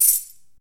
zulip/shaker.mp3 at e9ce69aaf62c6da4557aaf0cf5eb8c83cfdb81b7
shaker.mp3